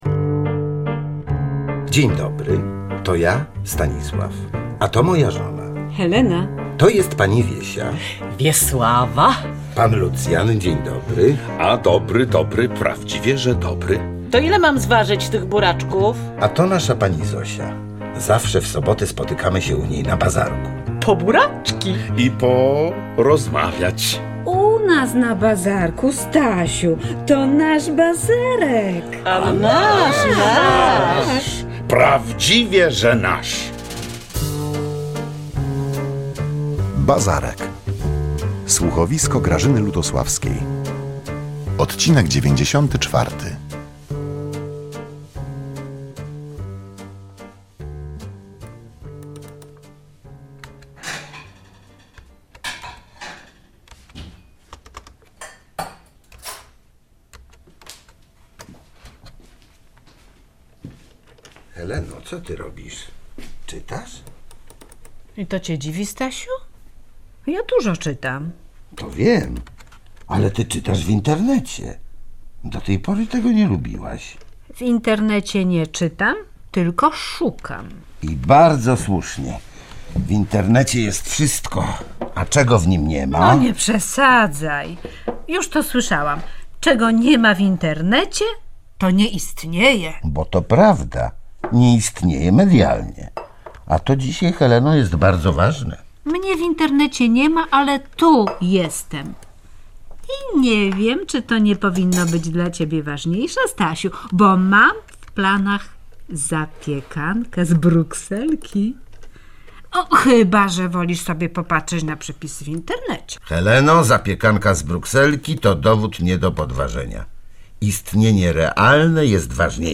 13.11.2016 „Bazarek” – Słuchowisko